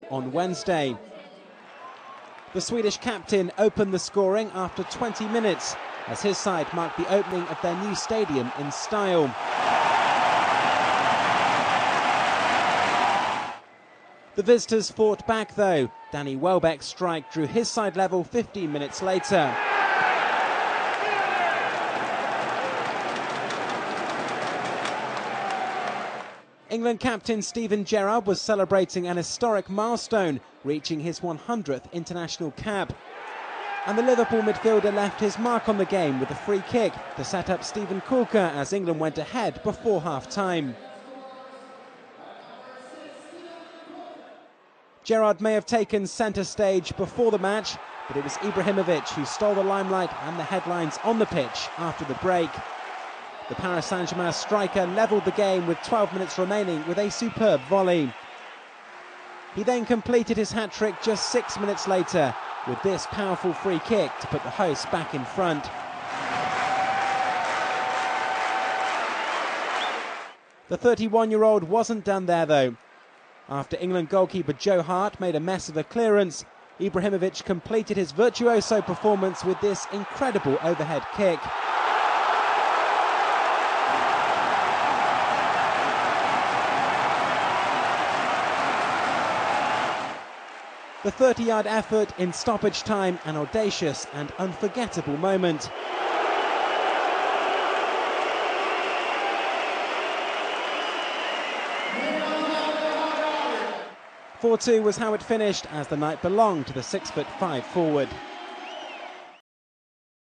08. Zlatan Ibrahimovic Interview